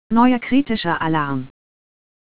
AlarmCritical.wav